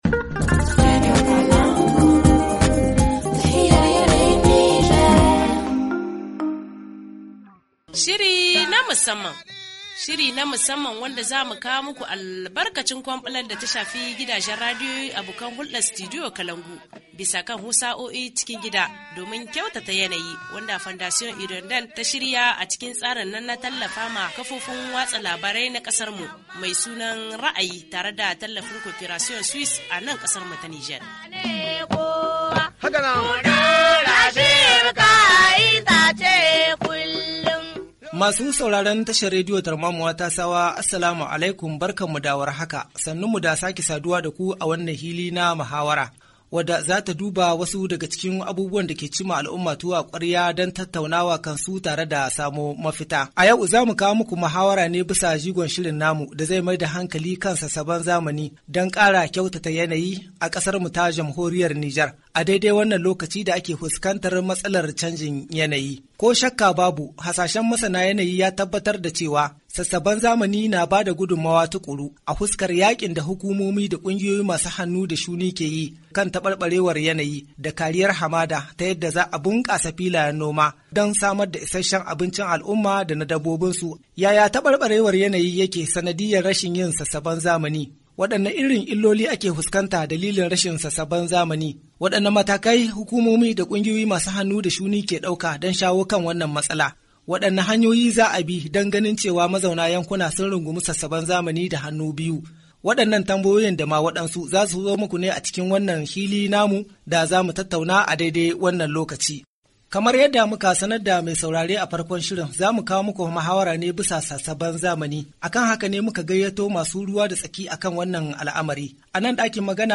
ok-HA-FORUM-REGENERATION-NATURELLE-RNA-TESSAOUA-0912.mp3